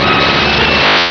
pokeemerald / sound / direct_sound_samples / cries / moltres.aif
-Replaced the Gen. 1 to 3 cries with BW2 rips.